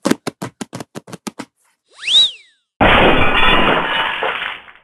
Funny Running Footsteps Slip into Glass Bottles and Crash
accent accident break cartoon comedy crash feet foley sound effect free sound royalty free Funny